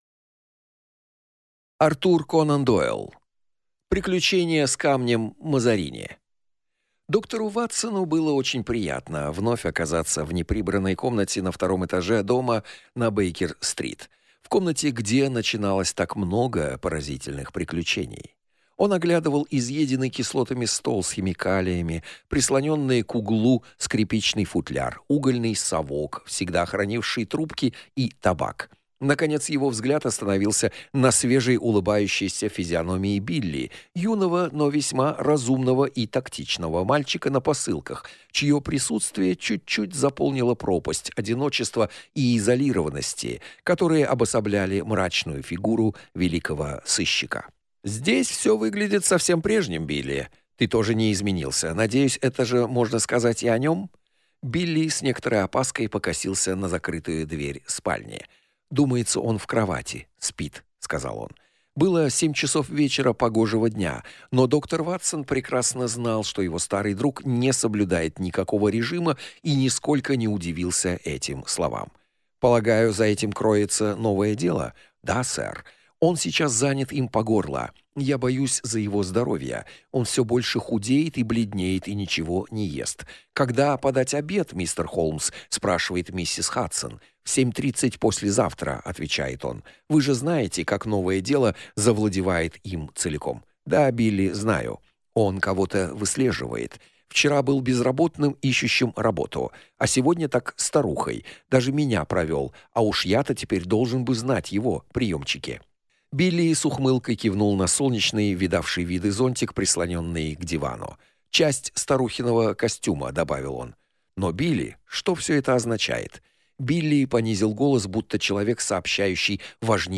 Аудиокнига Приключение с камнем Мазарини | Библиотека аудиокниг
Прослушать и бесплатно скачать фрагмент аудиокниги